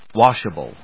音節wash・a・ble 発音記号・読み方
/wάʃəbl(米国英語), wˈɔːʃbl(英国英語)/